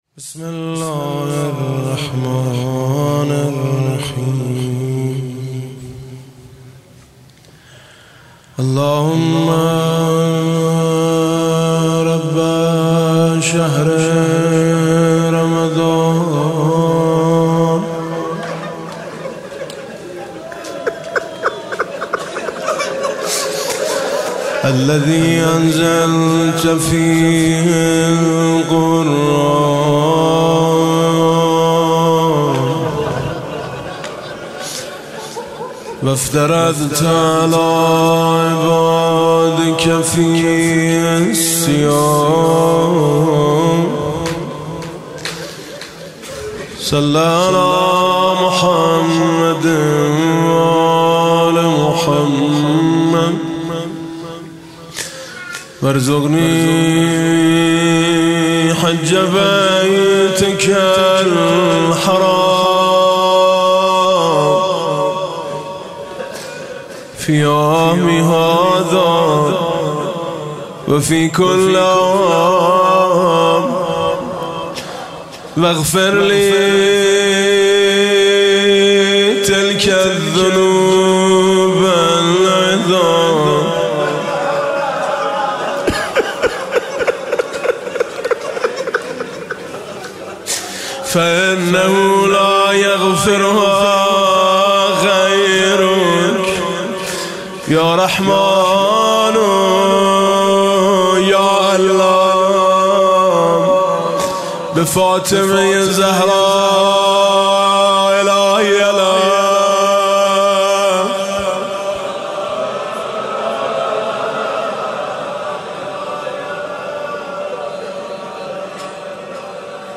16 اردیبهشت 98 - هیئت میثاق با شهدا - اللّهُمَّ رَبَّ شَهْرِ رَمَضانَ
حاج میثم مطیعی